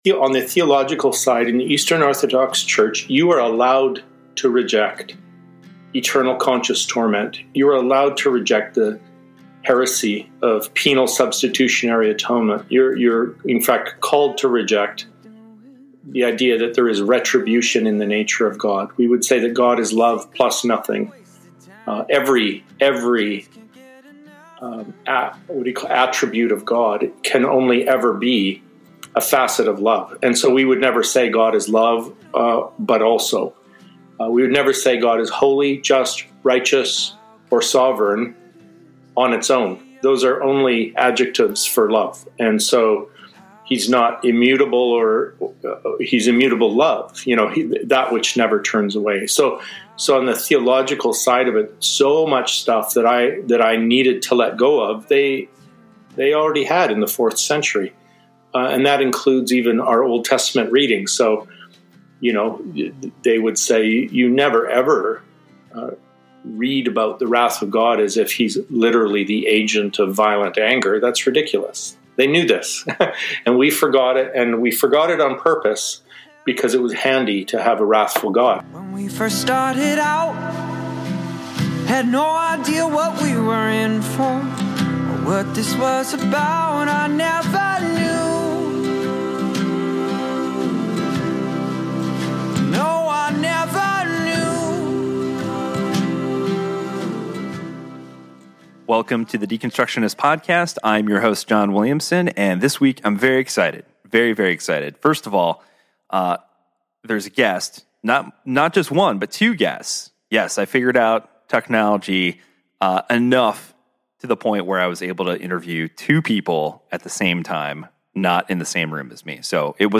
Guest Info/Bio: This week I not one, but two guests!